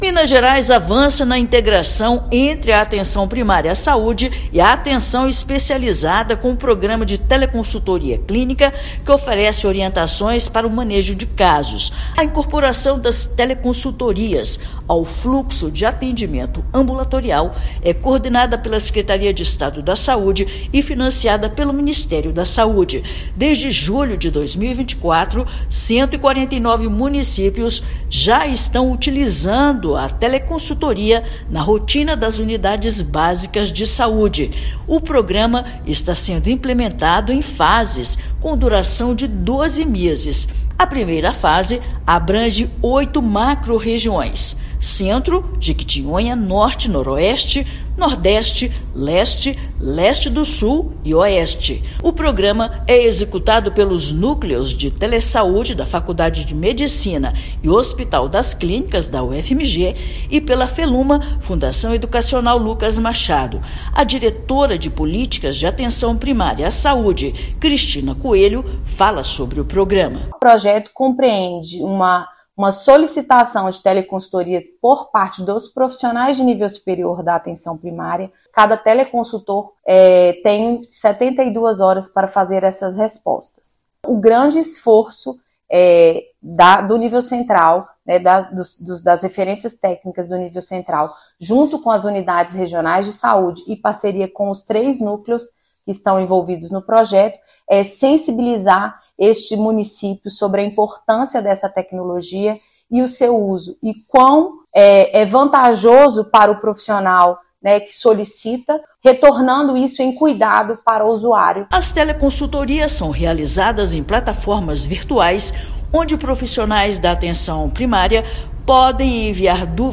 Ferramenta qualifica cuidado na Atenção Primária com orientação de especialistas e redução de encaminhamentos. Ouça matéria de rádio.